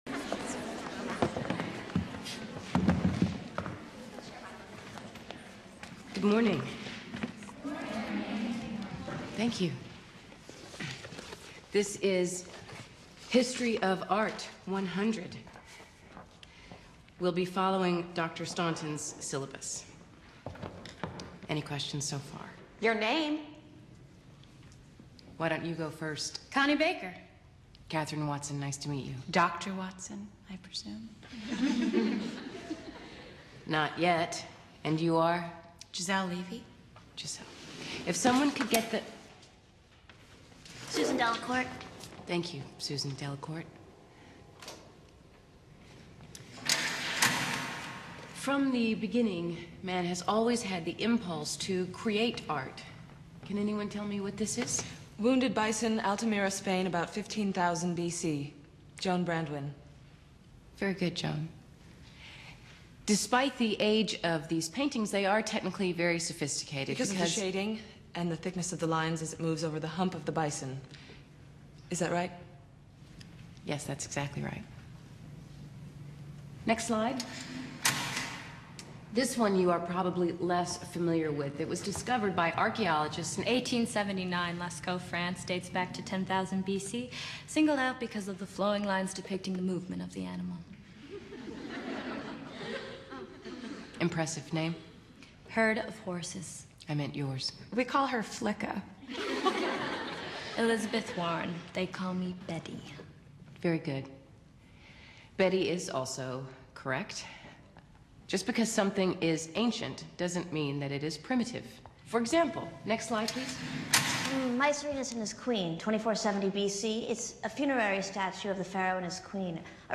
History of Arts teacher Katherine Ann Watson (Julia Roberts) teaches her first lecture at Wellesley College. The students know all the material already.